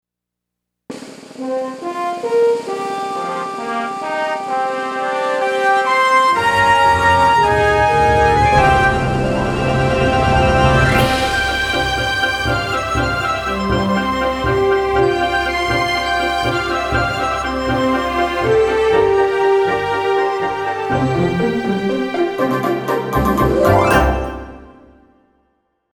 TV and Radio Jingles